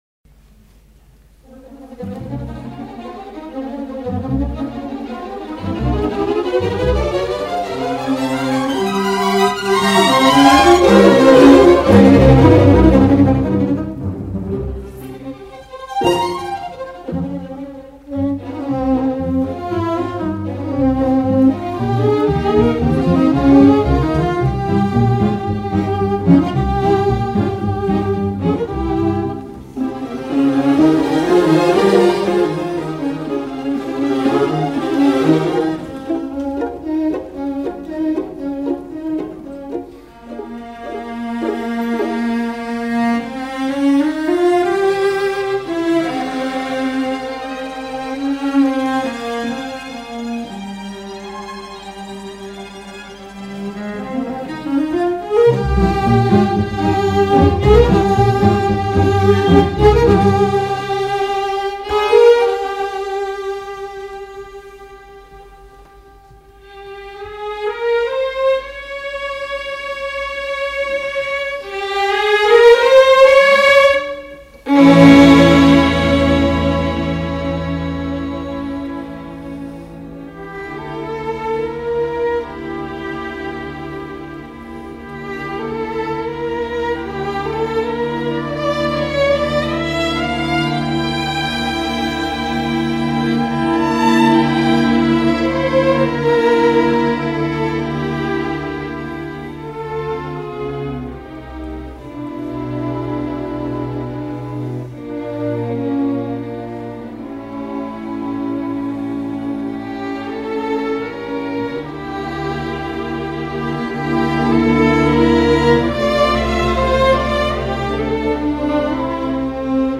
Orchestra, Cosenza Teatro Rendano 28/3/99